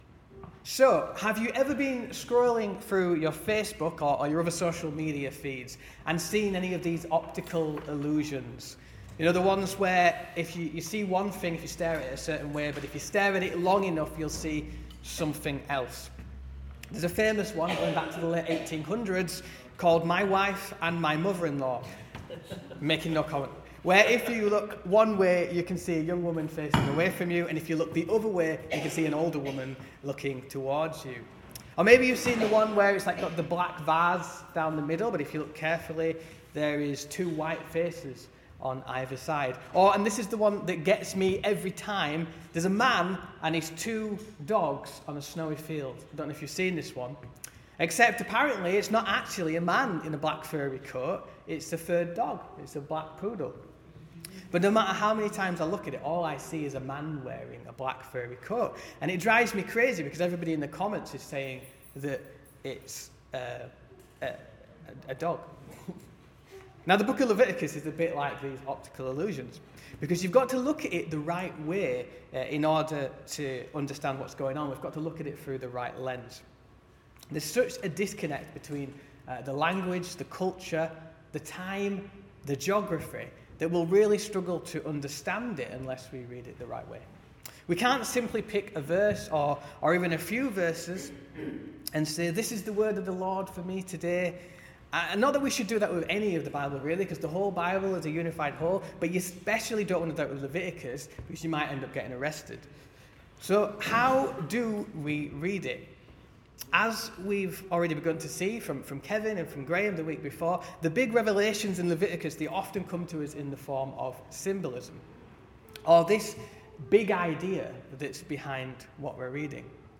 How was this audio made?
Returning Home Passage: Leviticus 6:8-7:38 Service Type: Morning Service « Who has clean hands?